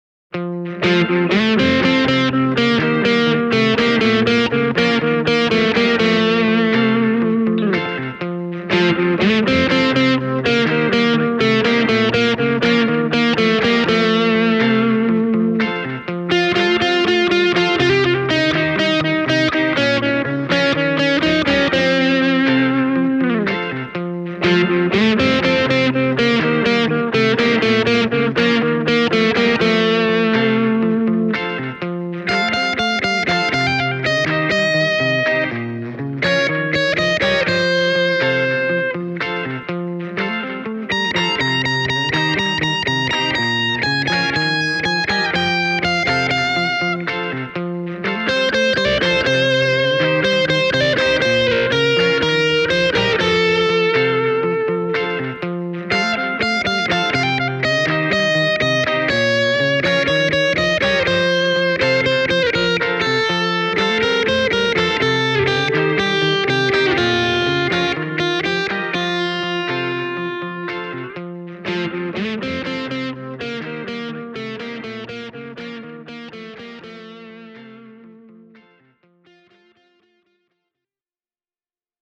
Äänitin T-Rex Replicatorilla kaksi erityylistä demobiisiä, joista voi kuulla uutuuslaitteen soundeja bändisovituksen kontekstissa.